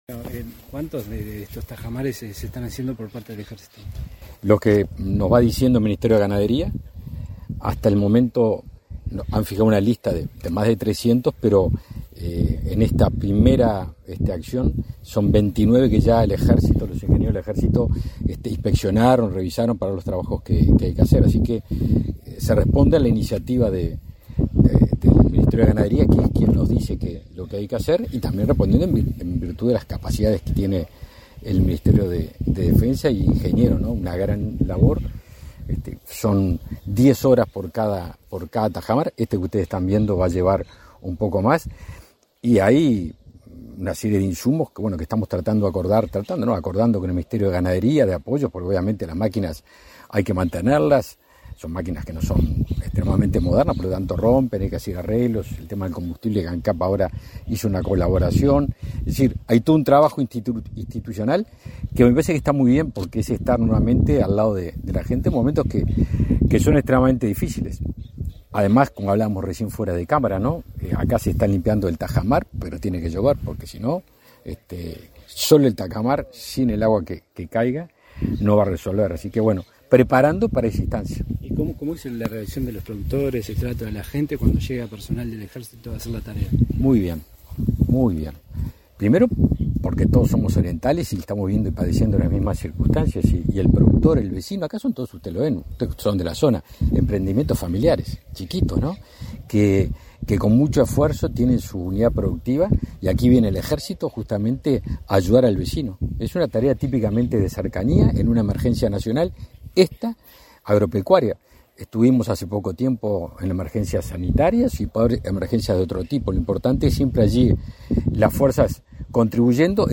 Declaraciones a la prensa del ministro de Defensa Nacional, Javier García